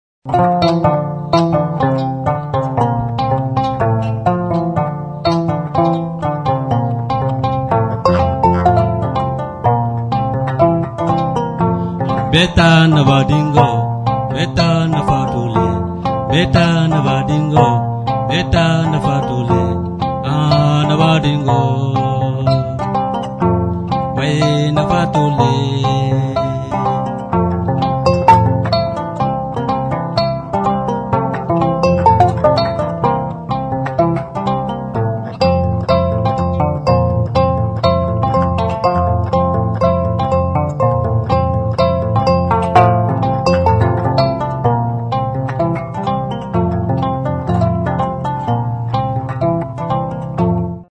Instrumentos de músicaKORA
Cordófonos -> Pulsados (con dedos o púas)
HM udazkeneko kontzertua.
KORA